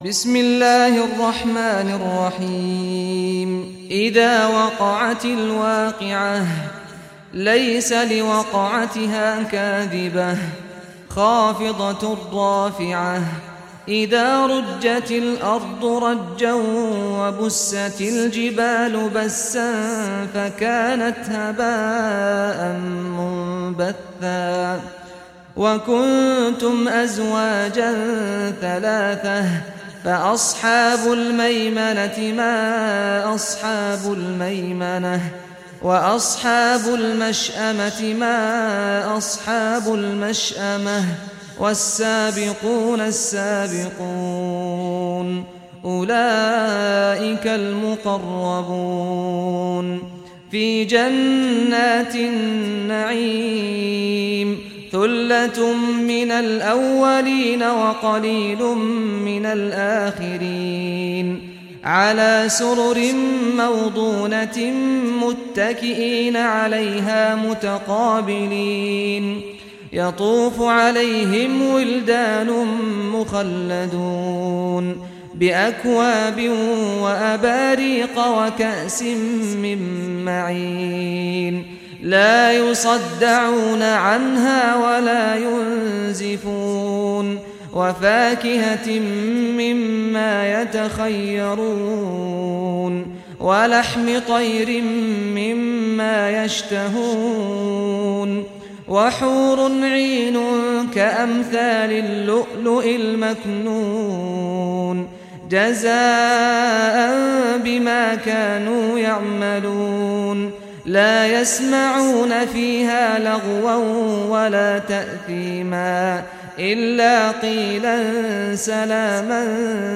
Surah Waqiah Recitation by Sheikh Saad al Ghamdi
Surah Waqiah, listen or play online mp3 tilawat / recitation in Arabic in the beautiful voice of Sheikh Saad al Ghamdi.